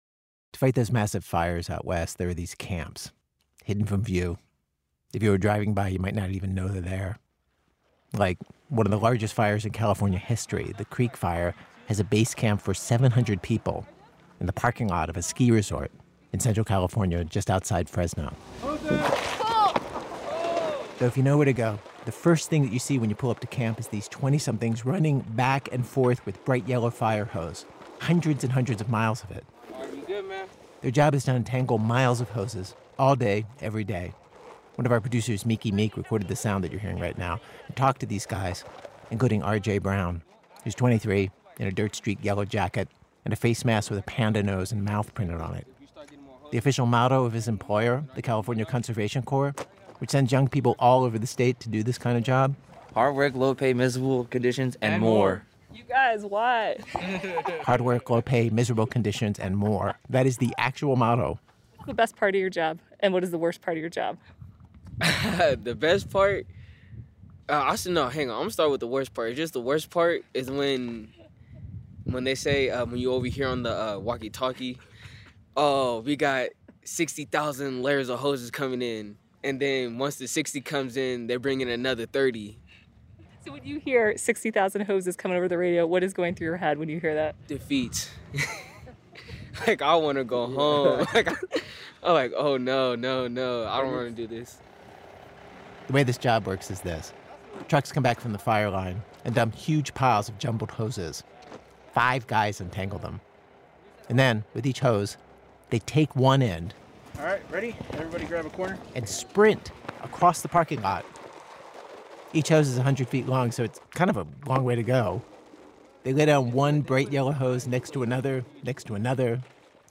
Note: The internet version of this episode contains un-beeped curse words.
We visited one of them in central California for the Creek Fire — one of the largest fires in the state’s history.